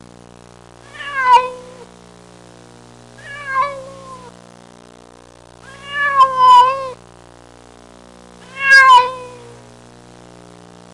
Cat Whine Sound Effect
Download a high-quality cat whine sound effect.
cat-whine-1.mp3